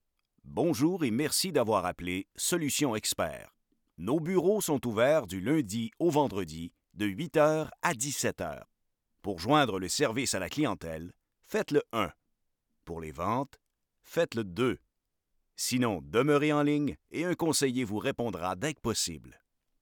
Französisch (Kanada)
Tief, Zuverlässig, Erwachsene
Telefonie